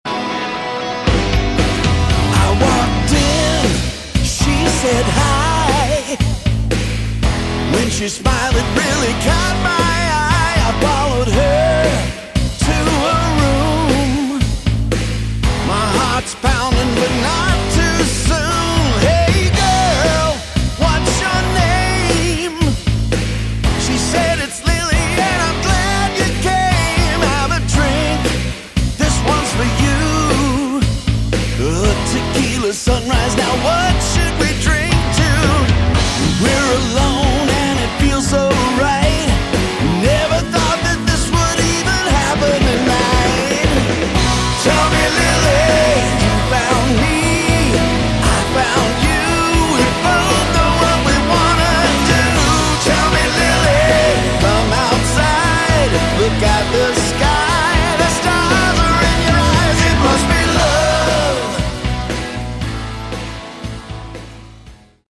Category: Hard Rock
lead and backing vocals, lead and rhythm guitars
bass, backing vocals, keyboards, drums, programming